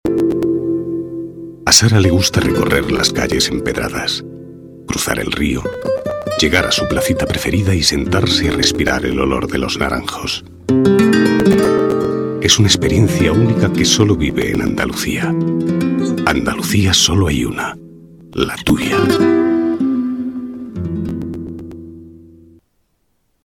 Voz grave y elegante.
kastilisch
Sprechprobe: Werbung (Muttersprache):
Deep voice and elegant.